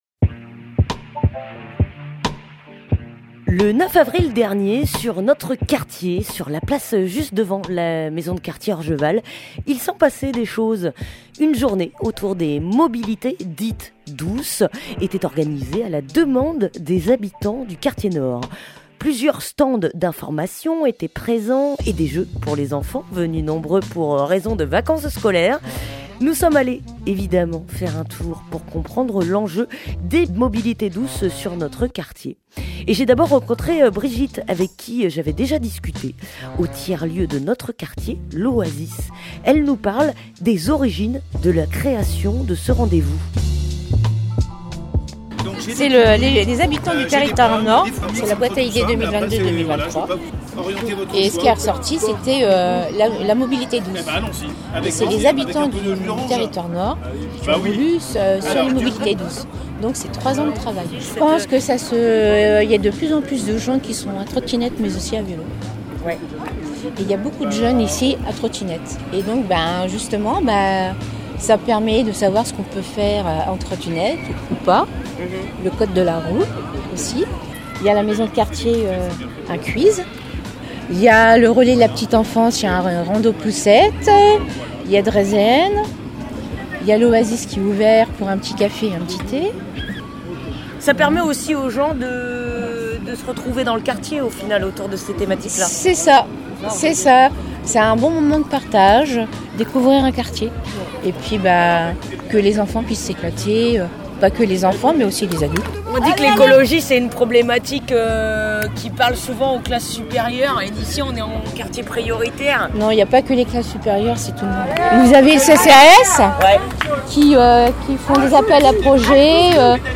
Reportage à Orgeval (14:48)
Radio Primitive vous propose un petit tour de certaines des associations présentes et des personnes qui s'y trouvaient.